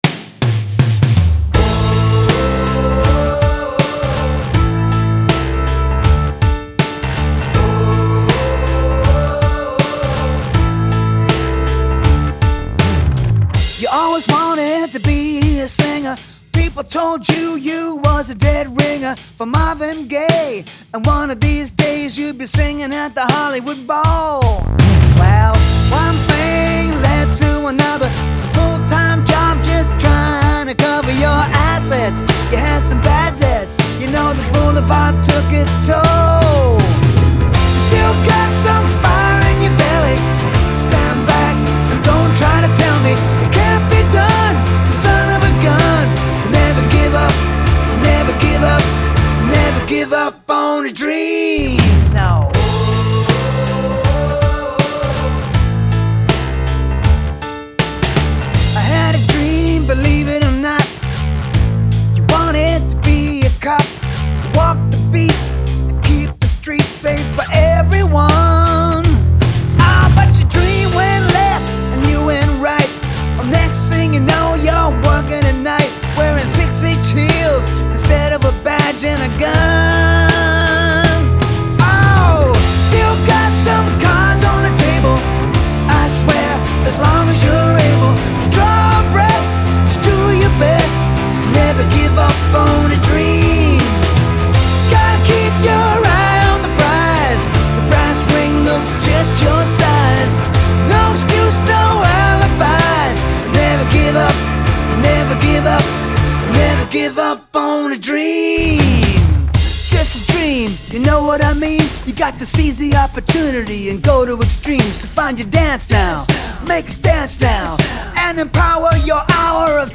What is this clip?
Genre : Comédies musicales